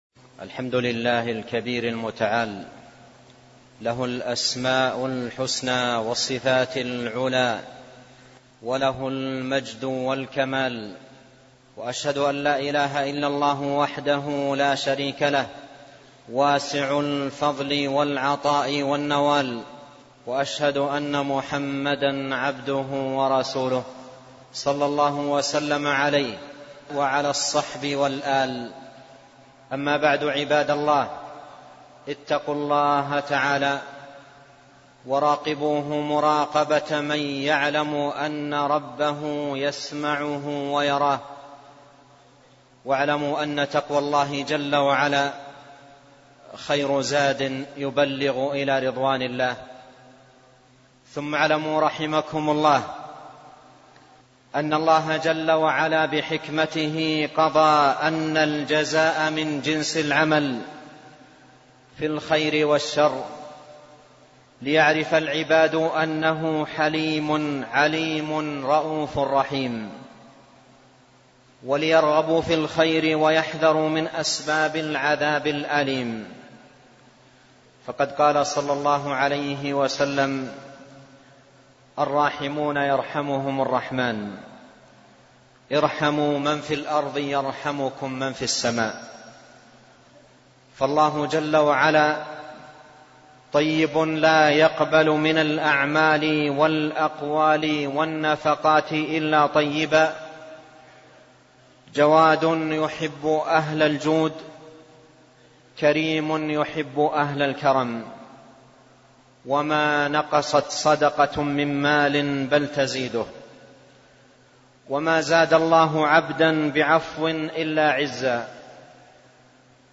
خطب متنوعة